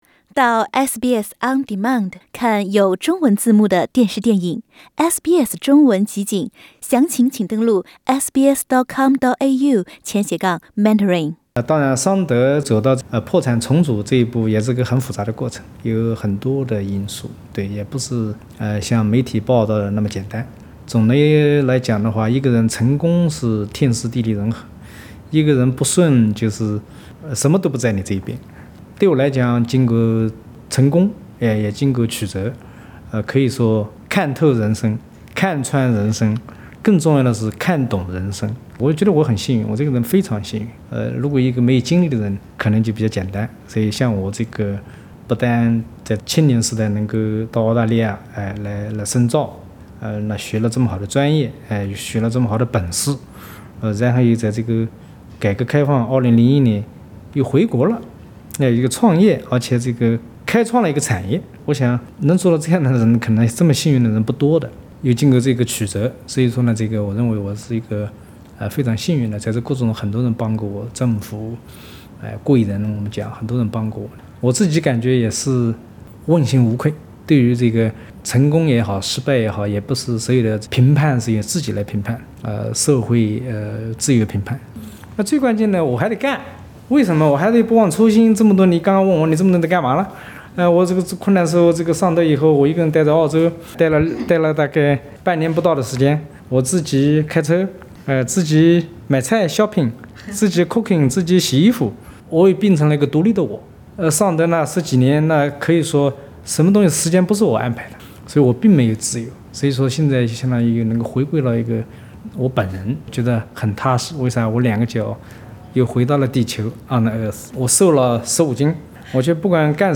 多年过去的今天，SBS 普通话再次有机会与施正荣博士面对面，不仅谈他的企业，也谈他这些年的日子。“